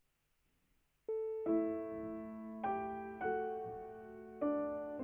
Al començament hi ha un fragment amb línia plana i el final està tallat.